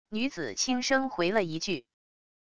女子轻声回了一句wav音频